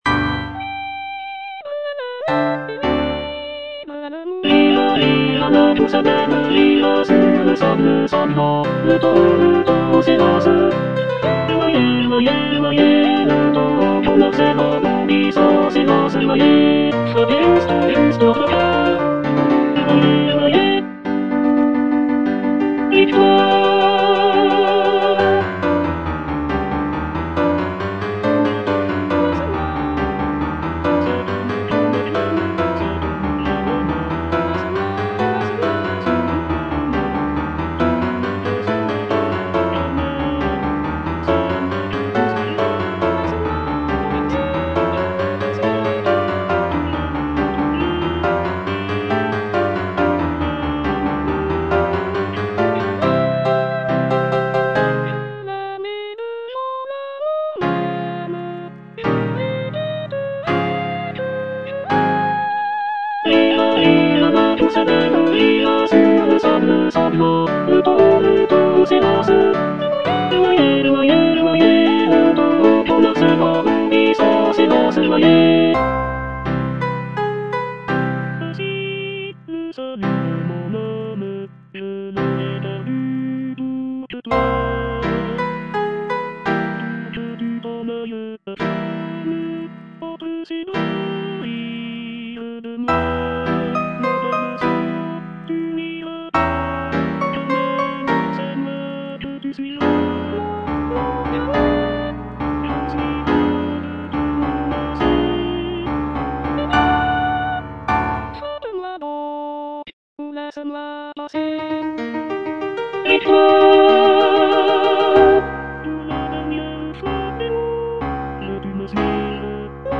Final chorus (tenor I) (Emphasised voice and other voices)